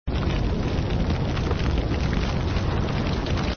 1 channel
4_firecrackling.mp3